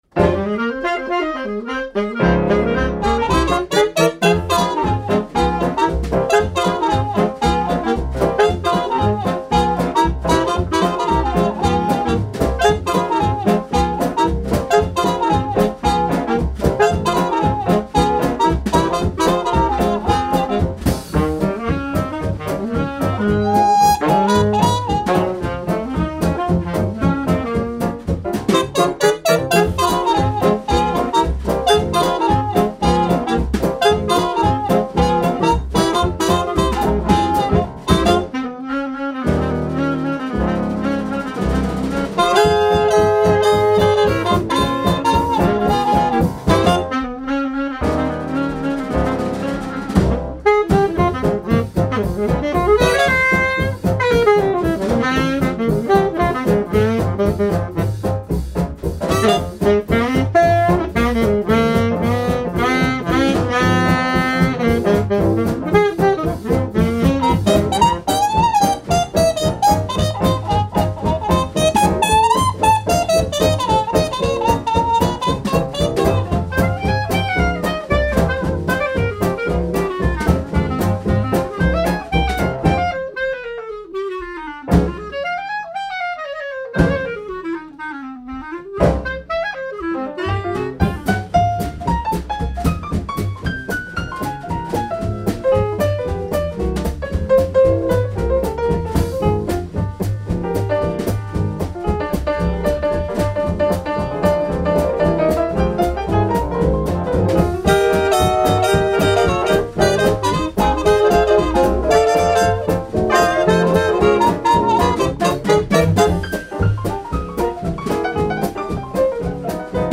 trompette
saxophone
clarinette
piano
guitare
contrebasse
batterie